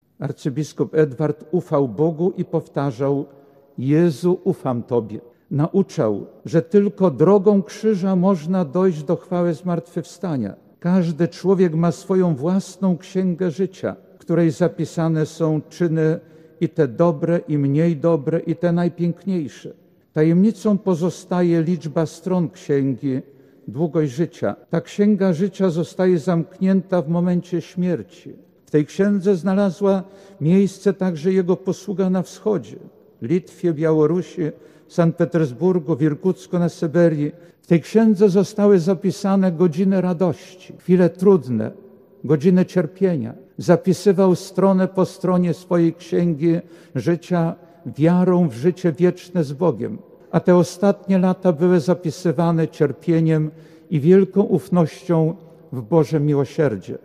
W czwartek, 17 października br. w Archikatedrze Białostockiej odbyły się główne uroczystości pogrzebowe śp. Arcybiskupa Seniora Edwarda Ozorowskiego.
Homilię wygłosi biskup ełcki Jerzy Mazur: